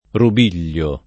rubiglio
rubiglio [ rub & l’l’o ] s. m. (bot.); pl. -gli